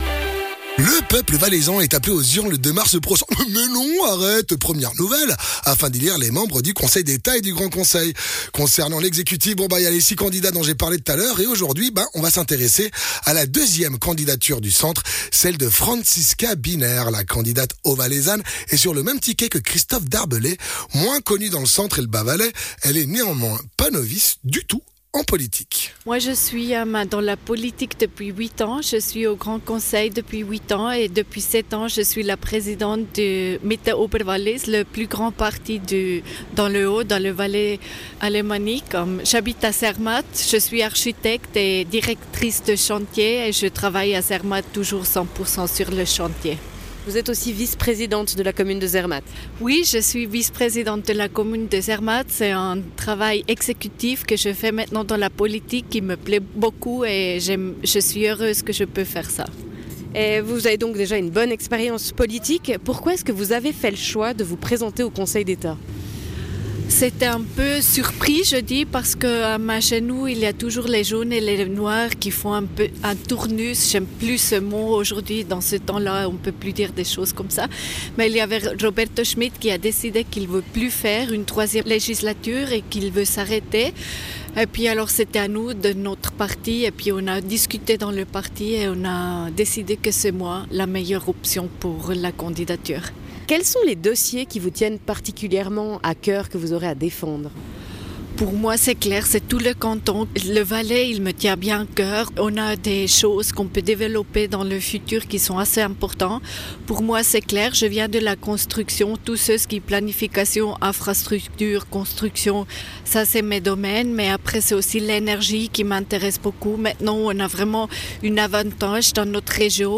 Election cantonale 2025 : entretien avec Franziska Biner
Intervenant(e) : Franziska Biner